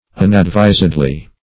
[1913 Webster] -- Un`ad*vis"ed*ly, adv. --